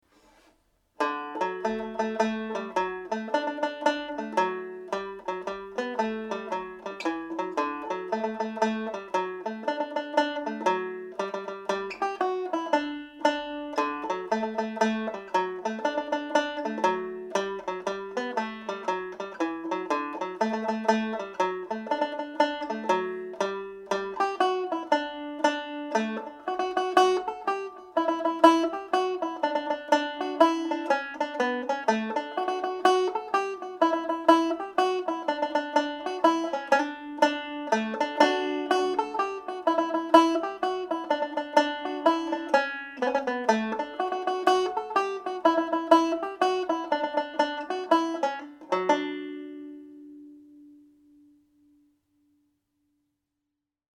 Sonny’s Mazurka played at mazurka speed